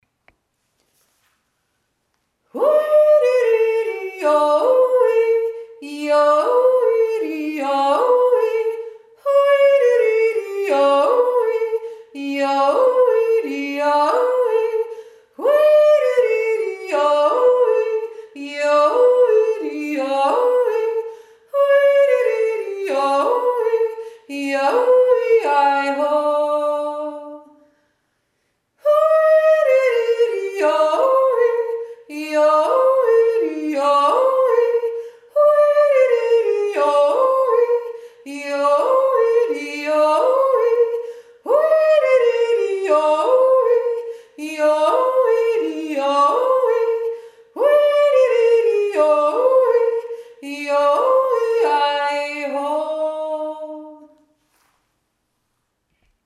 Goldegg jodelt März 2024
1. Stimme